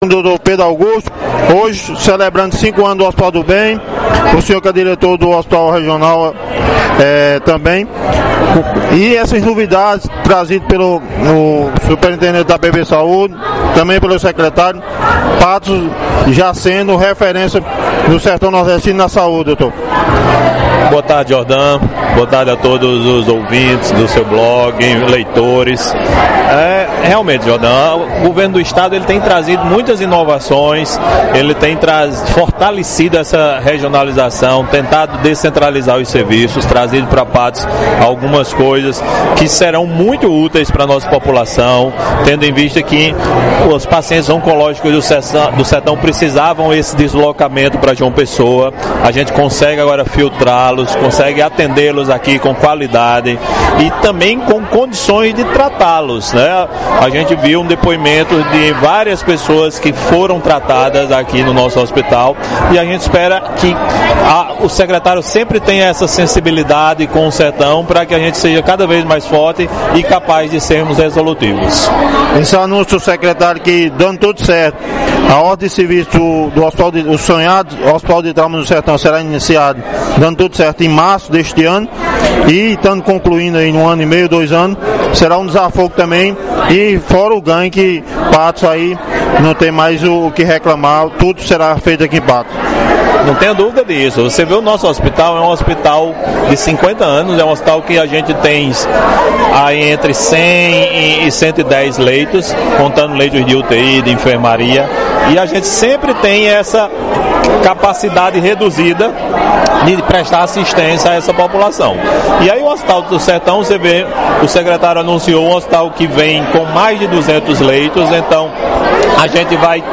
fazendo a cobertura do evento do 5⁰ aniversário do Hospital do Bem de Patos.